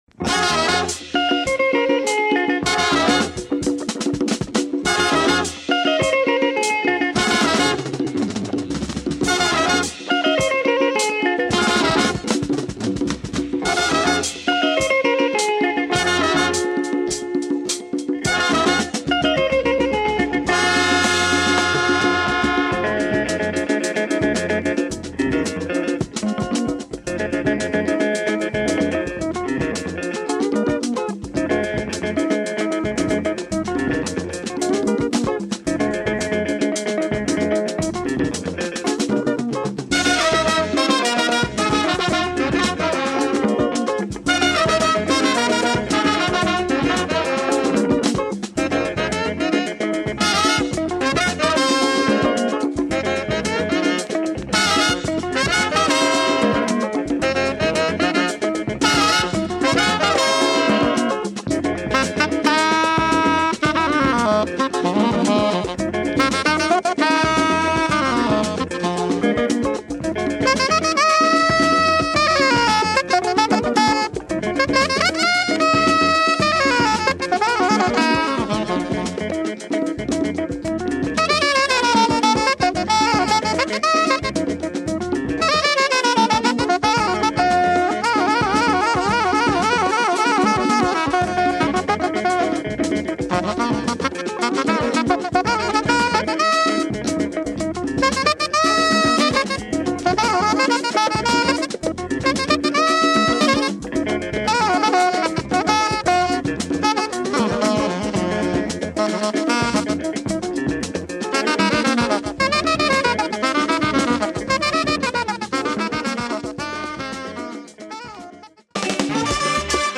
Fantastic groovy afro
Copy not mint but very enjoyable.